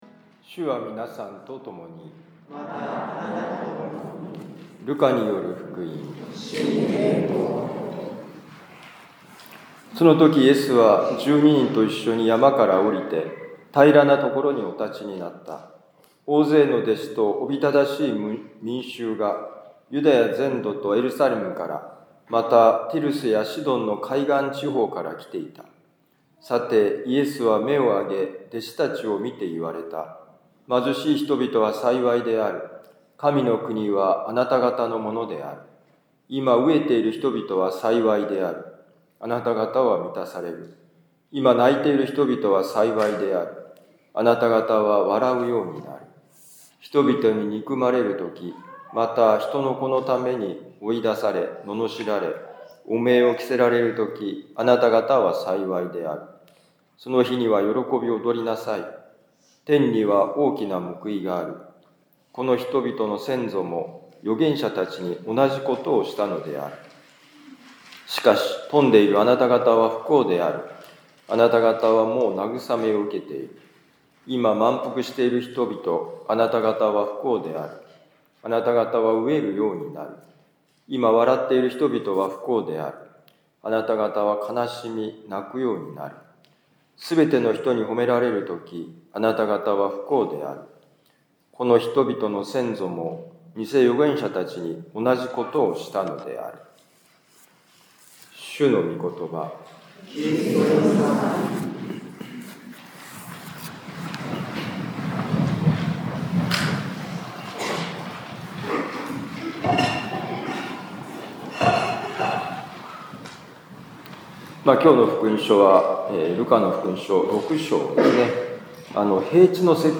ルカ福音書6章17、20-26節「貧しい人に与えられる幸い」2025年2月16日年間第６主日ミサ六甲カトリック教会